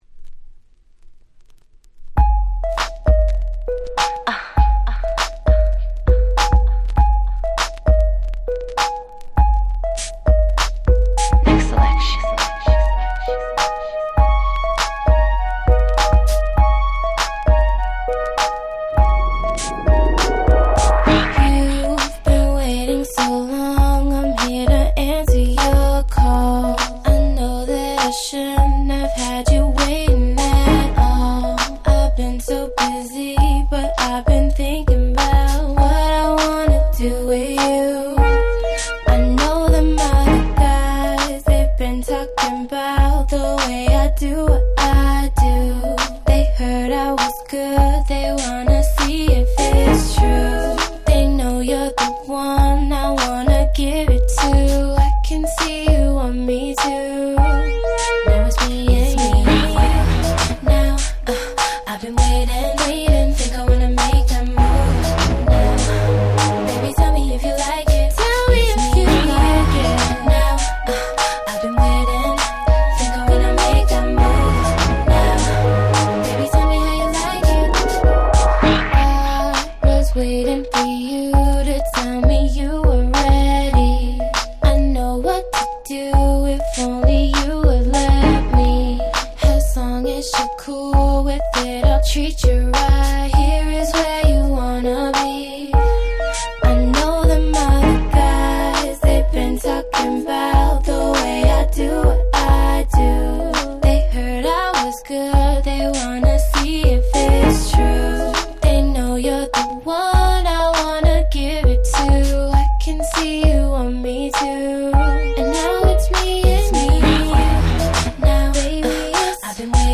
【Media】Vinyl 12'' Single
06' Super Hit R&B !!